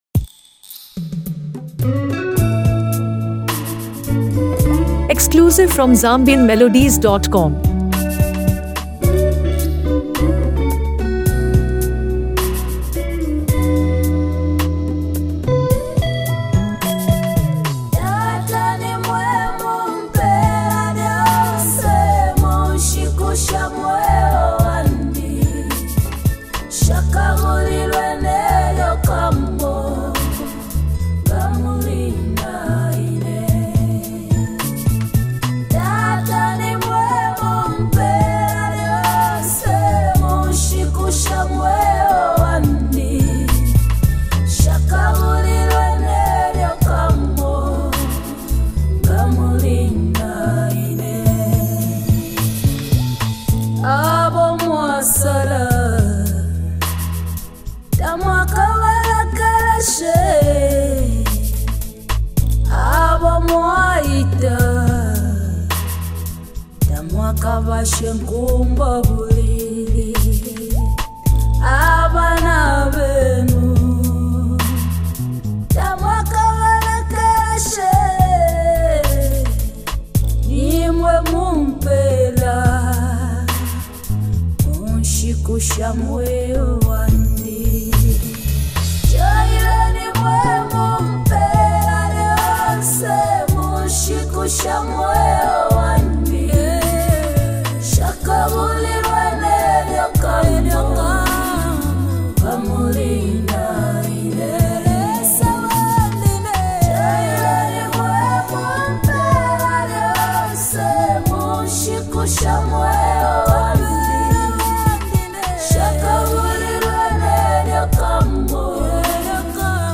and a smooth yet powerful vocal presence.